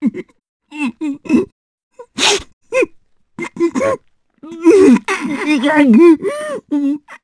Baudouin-Vox_Sad_jpb.wav